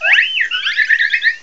cry_not_floette.aif